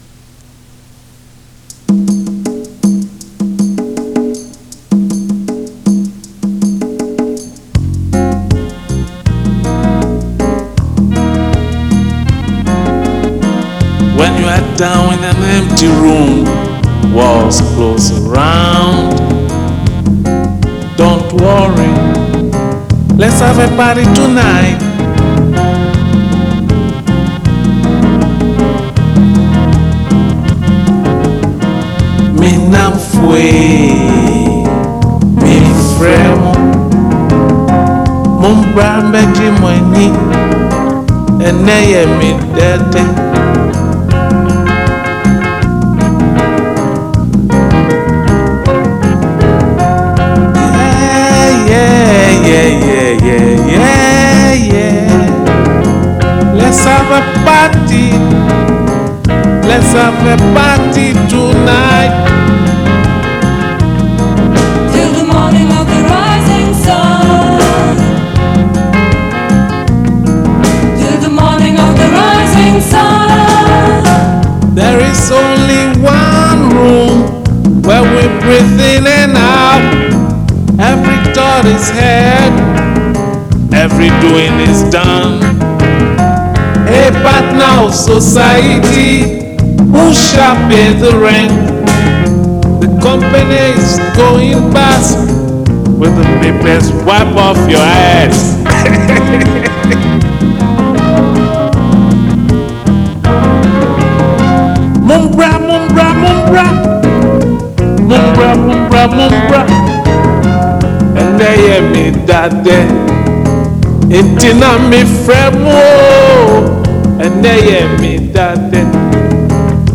bass, keyboards
vocals